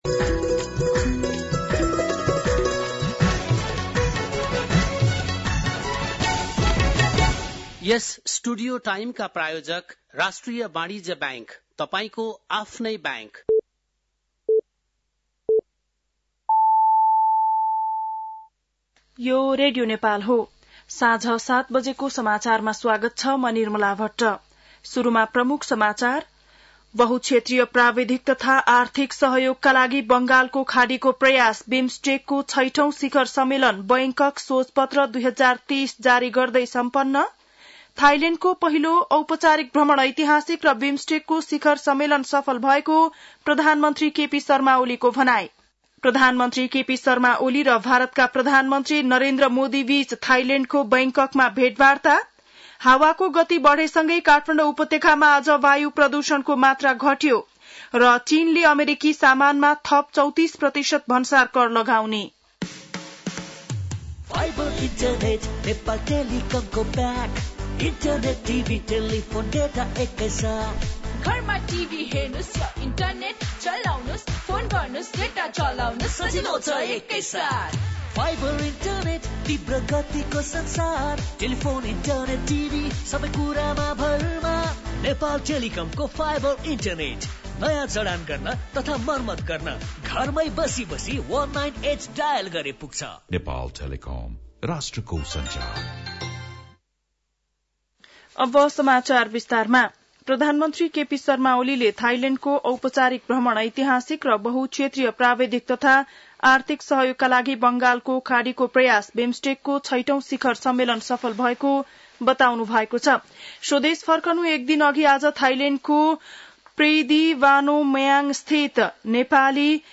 बेलुकी ७ बजेको नेपाली समाचार : २२ चैत , २०८१